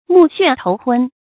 目眩頭昏 注音： ㄇㄨˋ ㄒㄨㄢˋ ㄊㄡˊ ㄏㄨㄣ 讀音讀法： 意思解釋： 猶頭昏眼花。